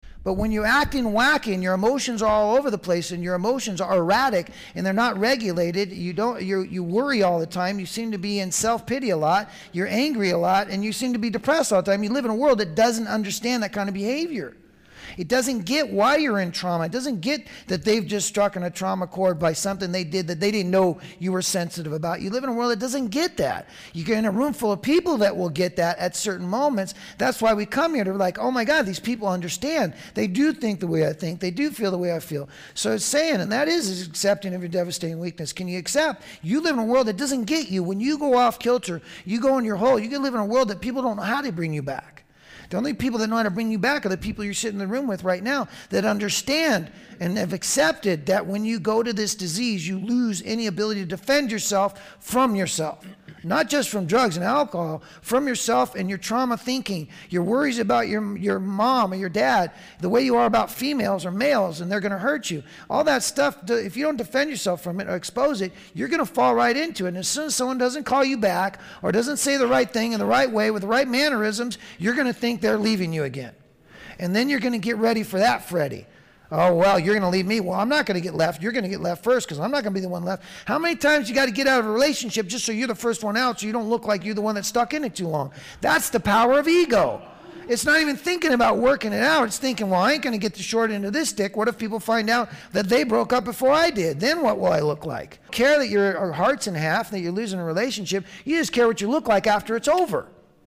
This audio archive is a compilation of many years of lecturing.
Within the lectures, you will hear people ask questions about why am I where I am, how can I get to a better place and what is blocking me.
A peaceful guided meditation that helps us to relax, quieten our mind, and connect with a deeper sense of stillness.